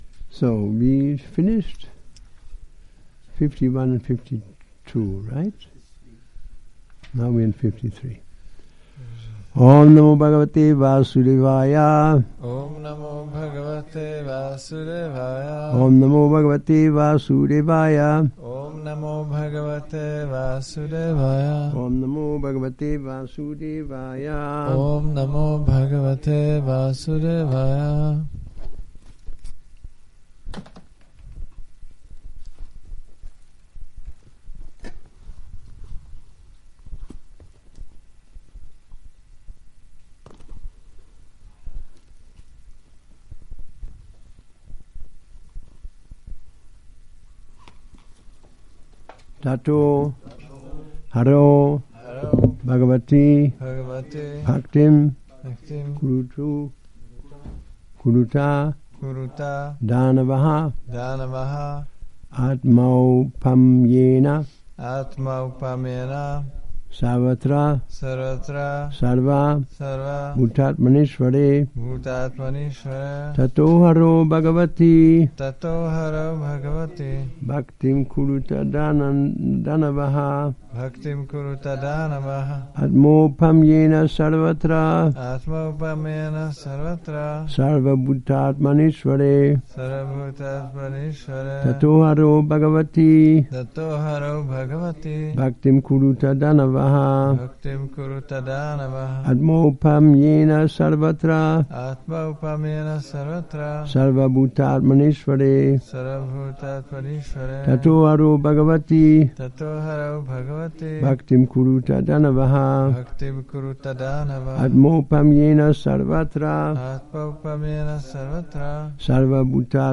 Přednáška SB-7.7.53 – Šrí Šrí Nitái Navadvípačandra mandir